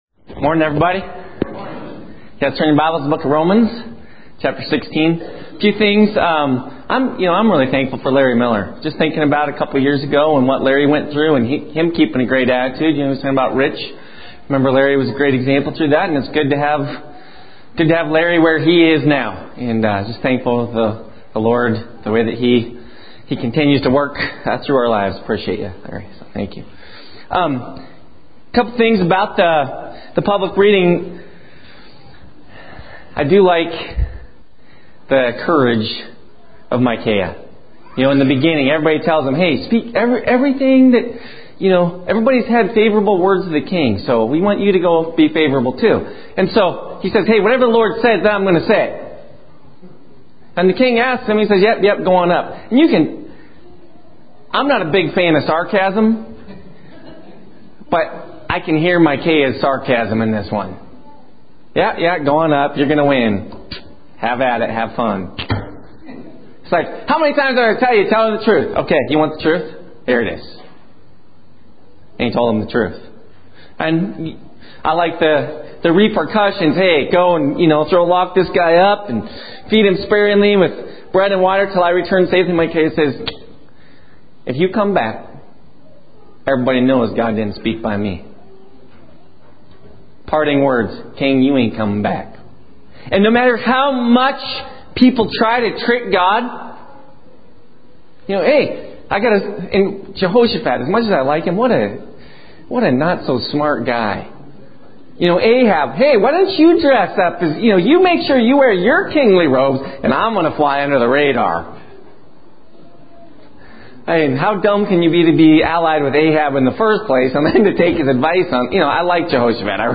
preaches an encouraging message on standing up agianst the devil in Romans 16.